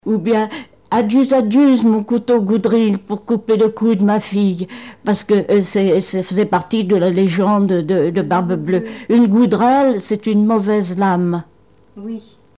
formulette enfantine : amusette
Pièce musicale inédite